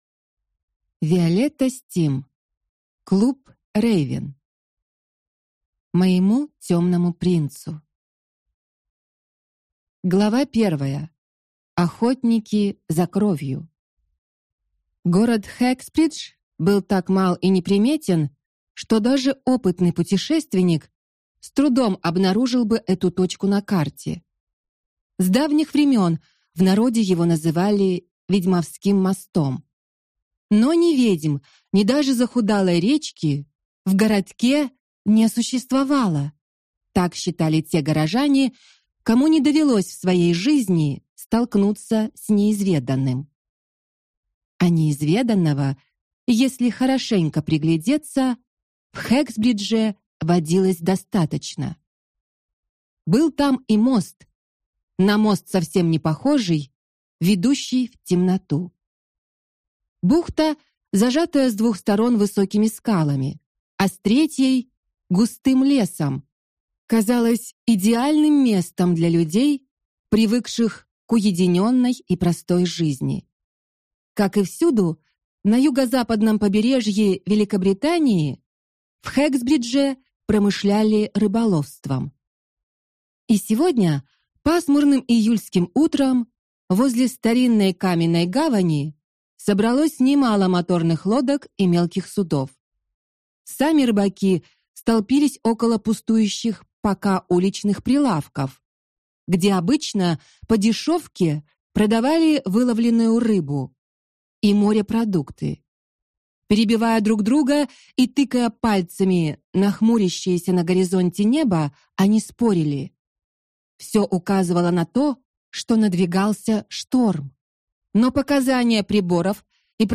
Аудиокнига Клуб Рейвен | Библиотека аудиокниг